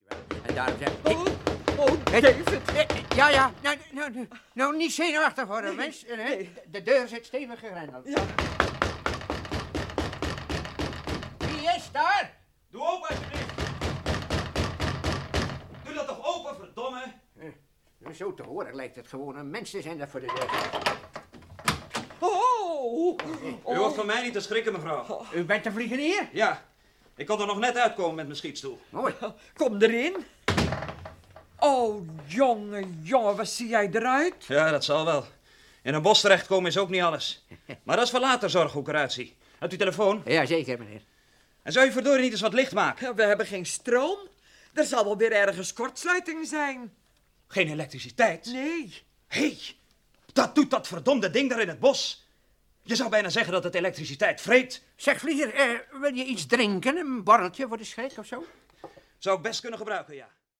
Rol(len) in de hoorspelreeks: